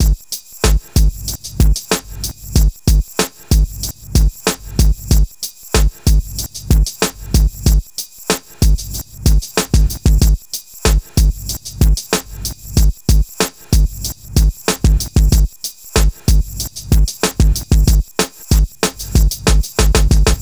Ridin_ Dubs - Beat 02.wav